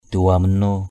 /d̪e̞-wa-ma-no:/ (d.) tên một hiệp sĩ trong văn chương Chàm = nom d’un héros de la littérature Cam.